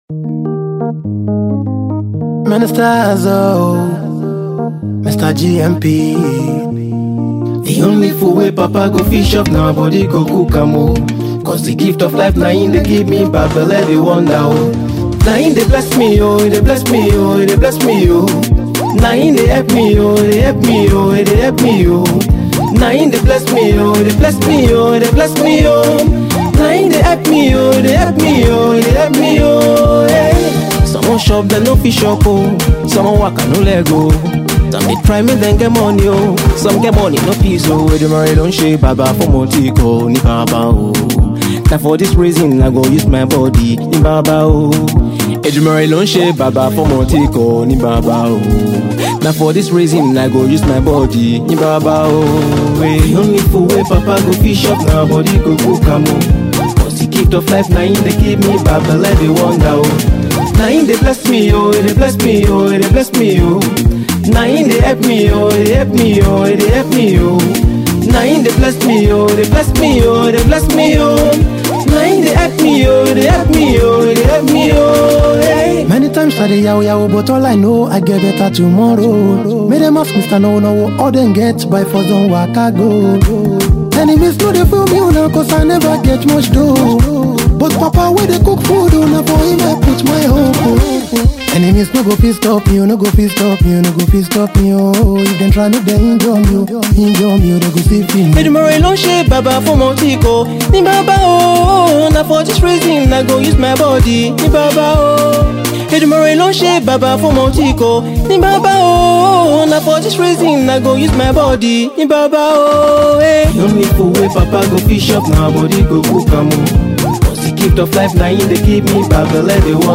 gospel single
contemporary Christian